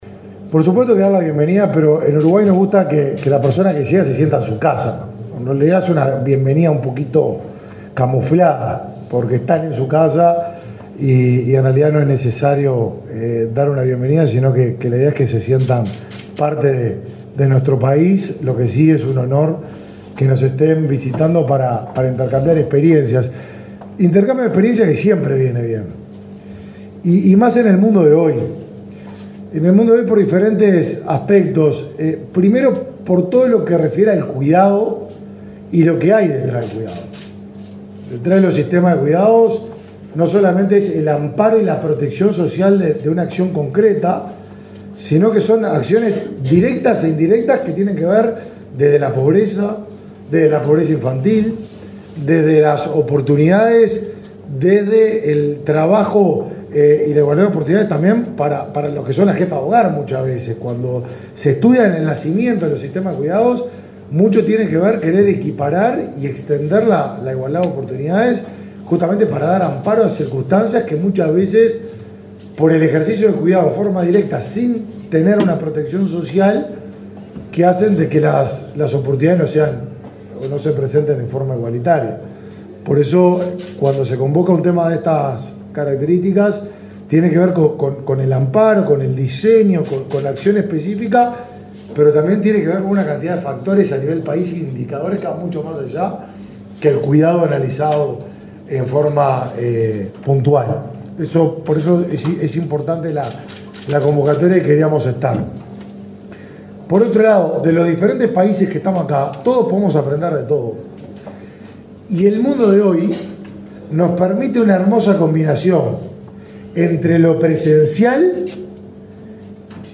Palabras del ministro de Desarrollo Social, Martín Lema
Palabras del ministro de Desarrollo Social, Martín Lema 20/11/2023 Compartir Facebook X Copiar enlace WhatsApp LinkedIn Uruguay recibe esta semana delegaciones de República Dominicana, Costa Rica, México, Cuba, Paraguay y de las oficinas del Fondo de Población de las Naciones Unidas, para intercambiar experiencias concretas sobre servicios y políticas públicas de cuidados. El ministro de Desarrollo Social, Martín Lema, participó, este lunes 20 en Montevideo, de la apertura de la jornada.